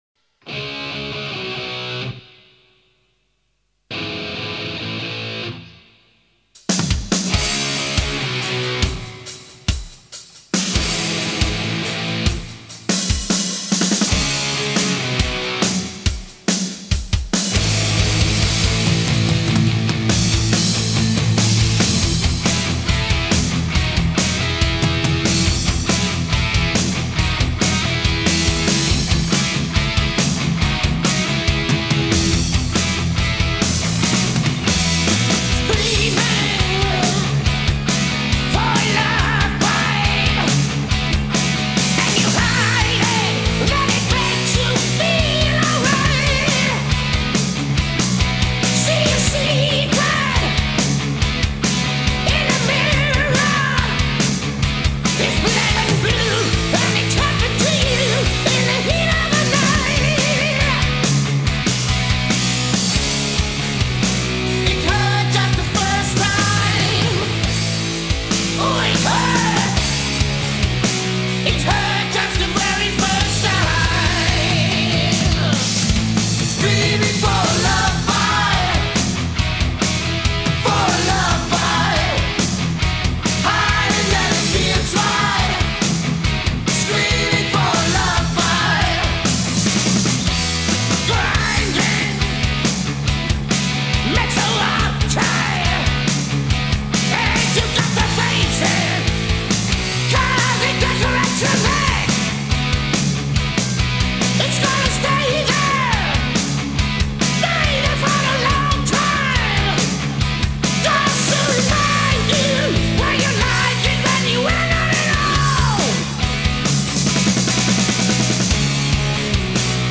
Хэви метал Heavy Metal